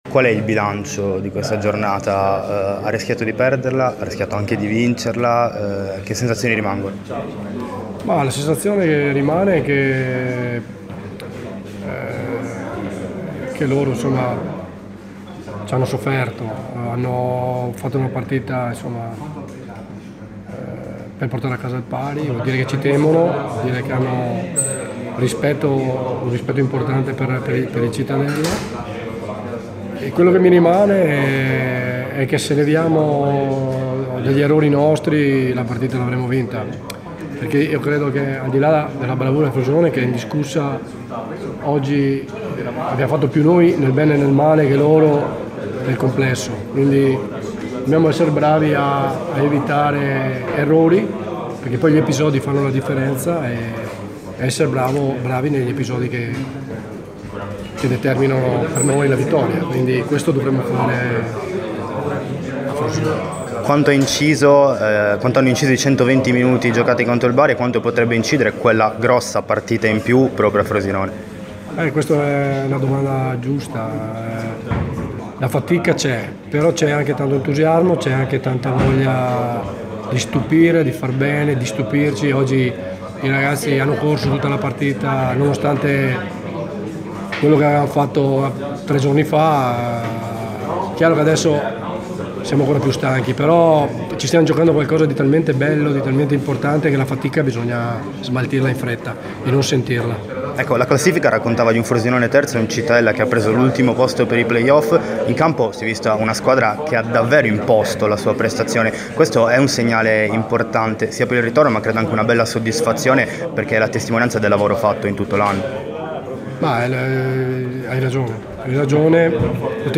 in esclusiva intervistato
al termine di Cittadella-Frosinone 1-1